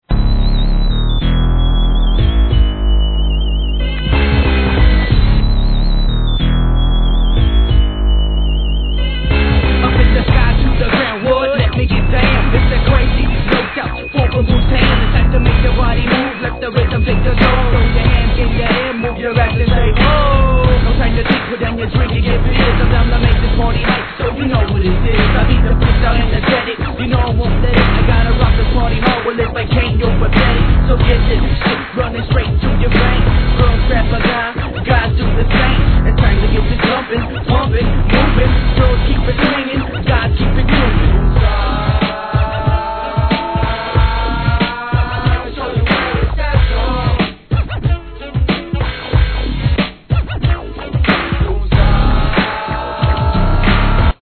G-RAP/WEST COAST/SOUTH
鉄板ピーヒャラにバシバシ・スネア!!